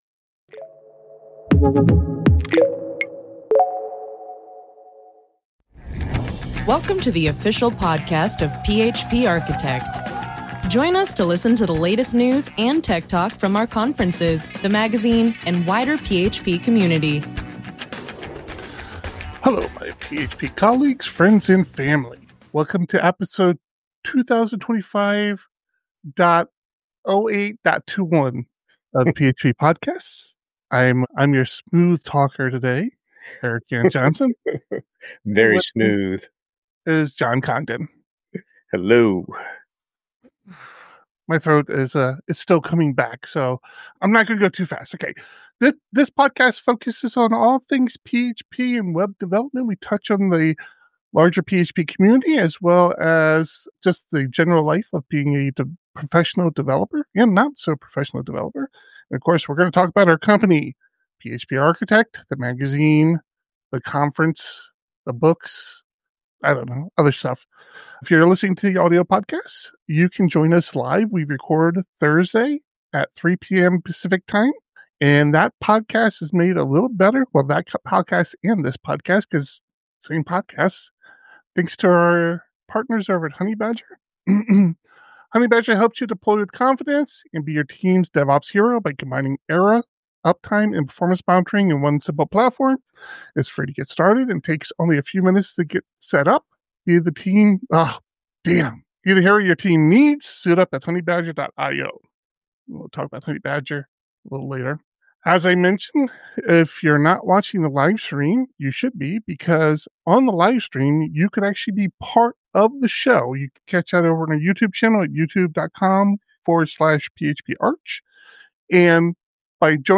The PHP Podcast streams the recording of this podcast live, typically every Thursday at 3 PM PT.